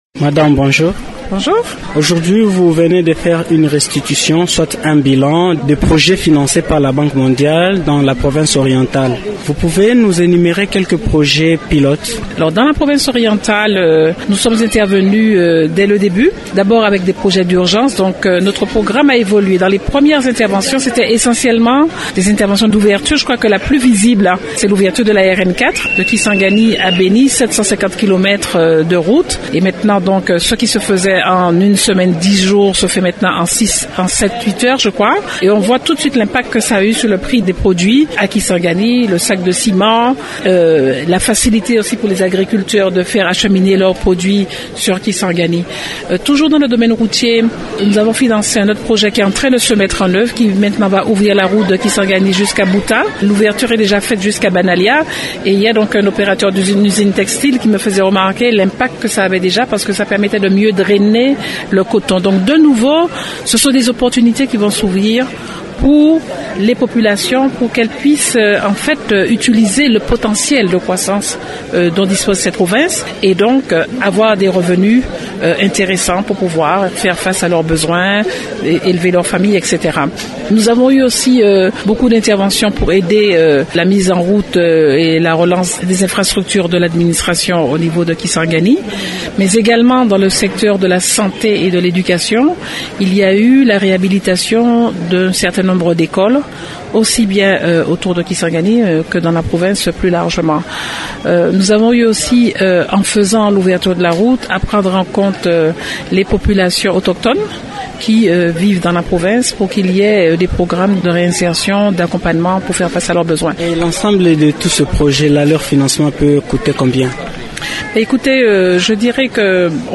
Dans l’entretien qu’elle a accordé à Radio Okapi,